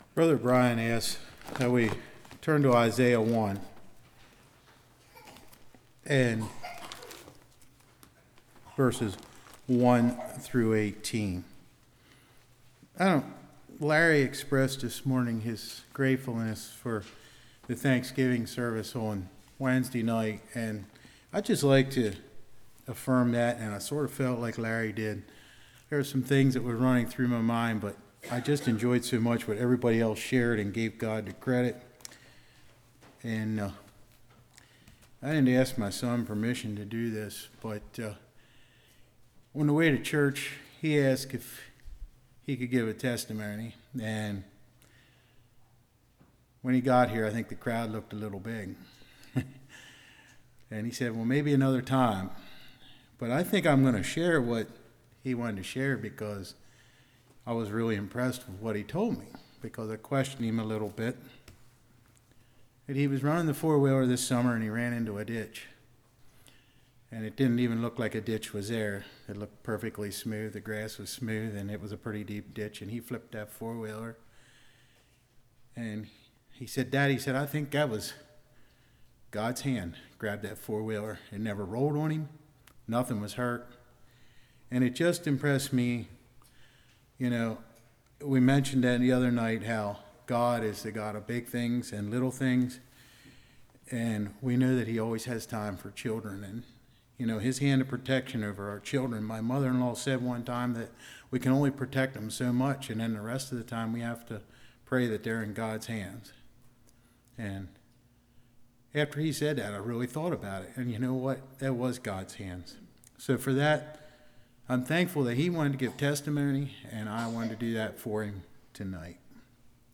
Isaiah 1:1-18 Service Type: Evening Judah Did Not Know God Is there sin in our Life?